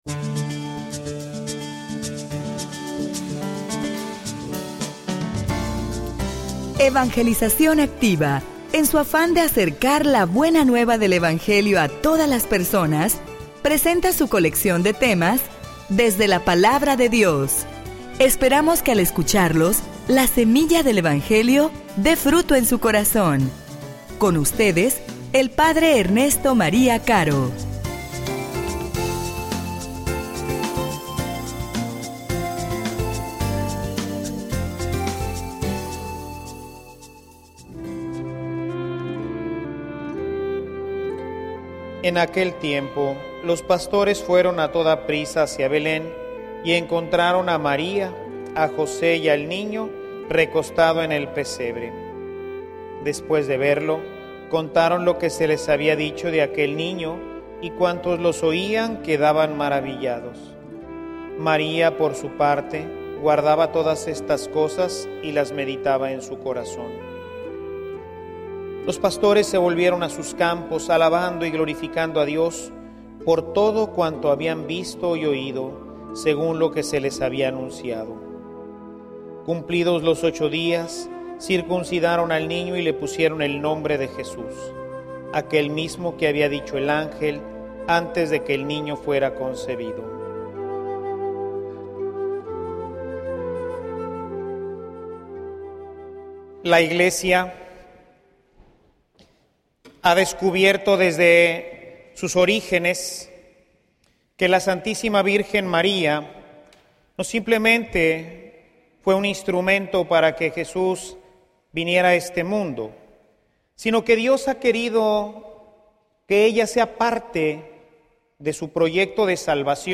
homilia_El_Arca_de_la_Alianza.mp3